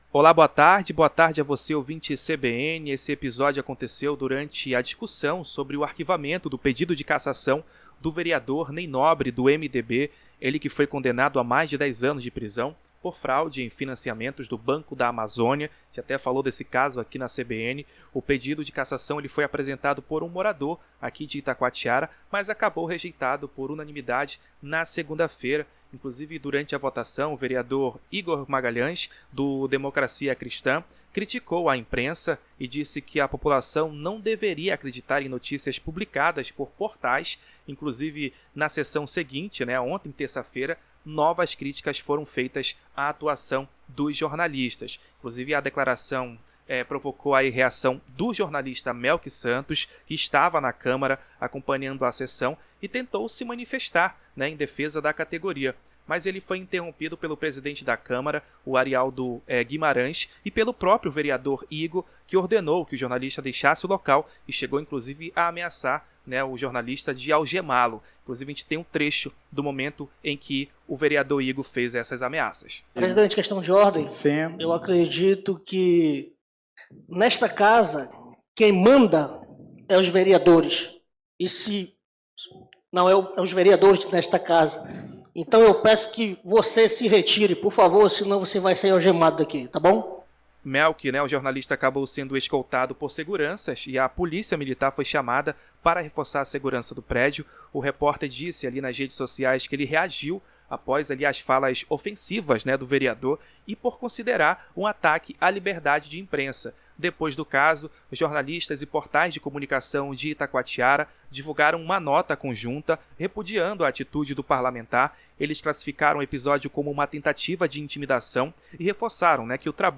Vereador expulsa repórter durante sessão na Câmara Municipal em Itacoatiara